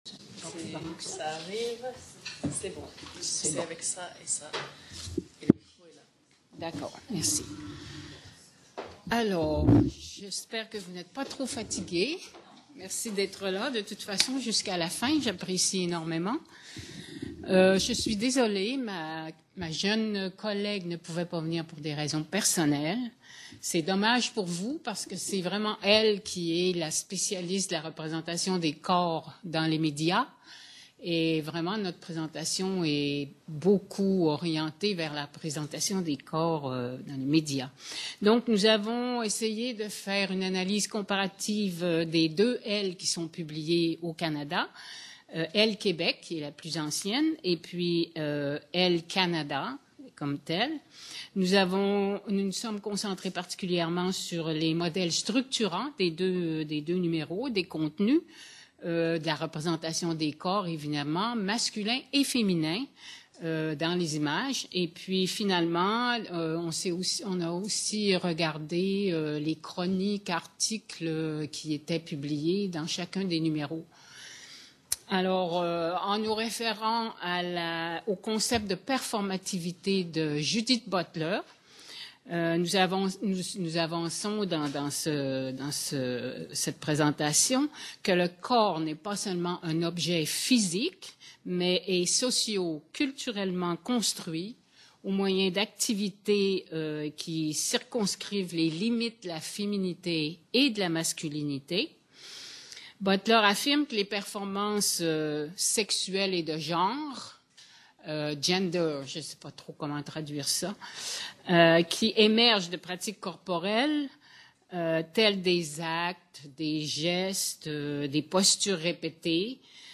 Colloque "Elle fête ses 70 ans"